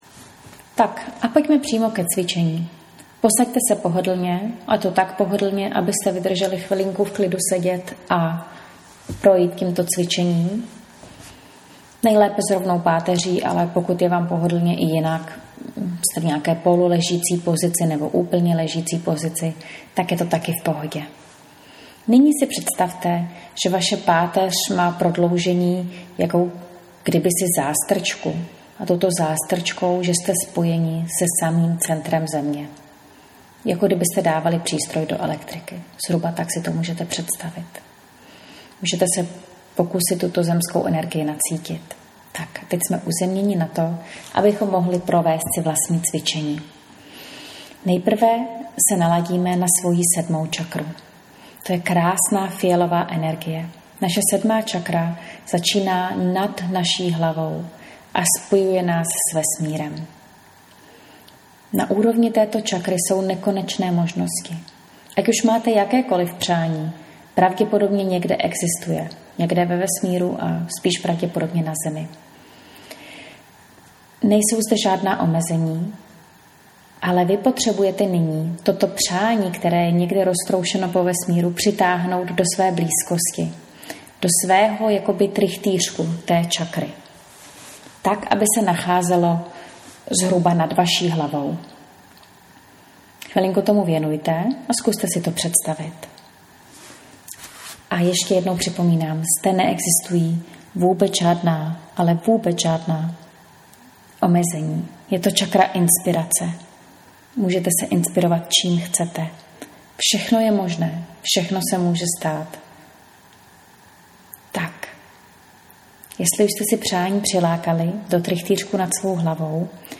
Tento rituál si můžeš nazvat meditací, řízenou meditací, vizualizací nebo prostě cvičením.
Nech se vést mým hlasem a vlastní představivostí.